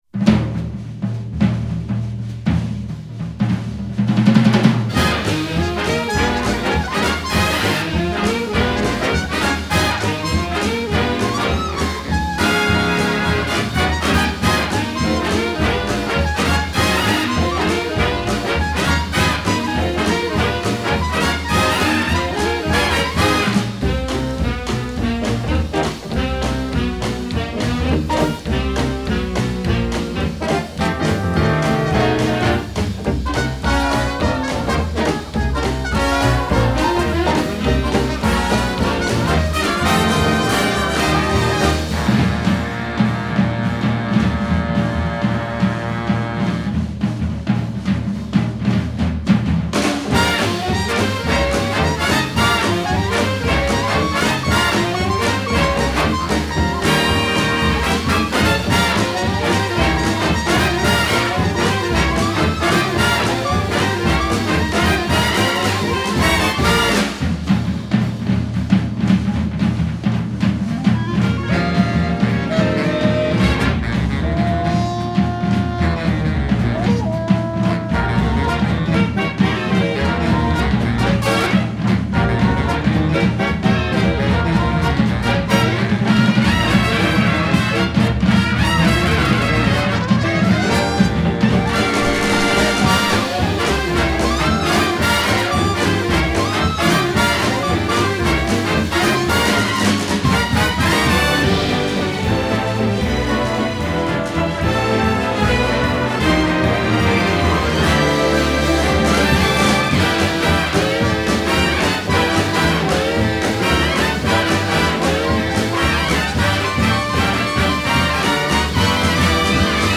ultra catchy
marvelous retro score